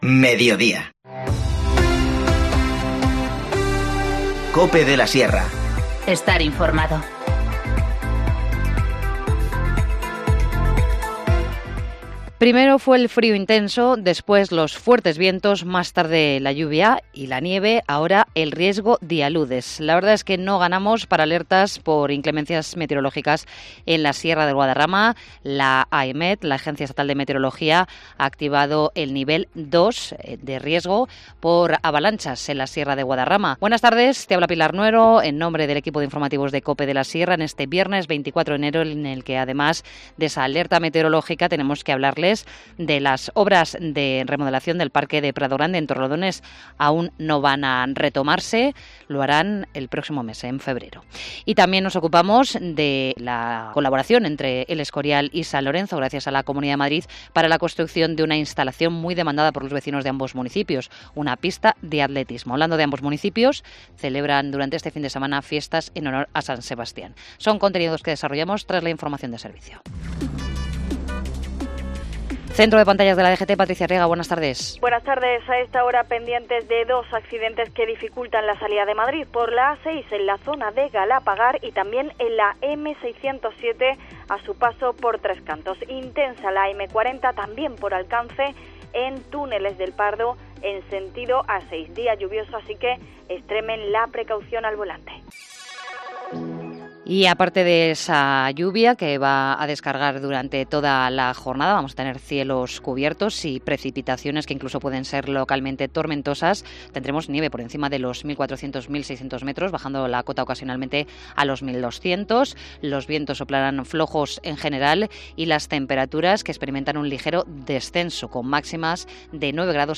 Informativo Mediodía 24 enero 14:20h